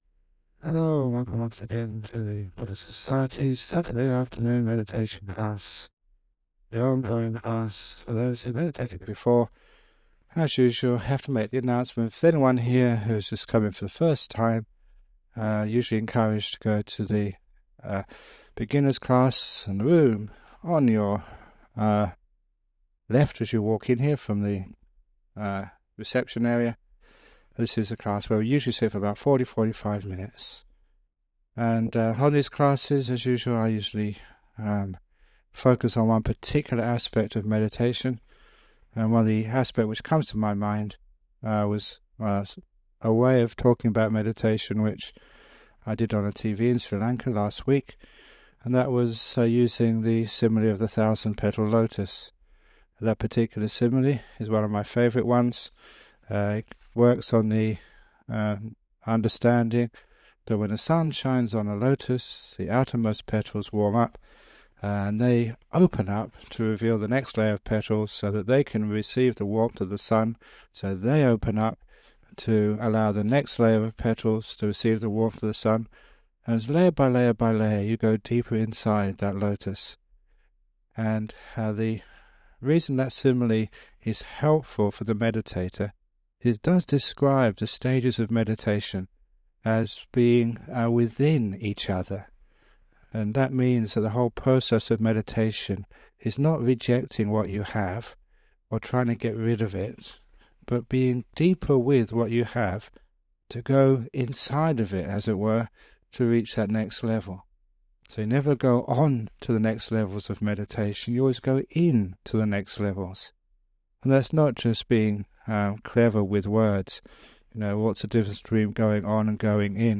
This guided meditation by Ajahn Brahm was originally recorded in 10th Februar 2007. It includes a talk about some aspect of meditation followed by a 45 minute guided meditation (starting at the 13 minutes mark). This guided meditation has been remastered and published by the Everyday Dhamma Network, and will be of interest to people who have started meditation but are seeking guidance to take it deeper.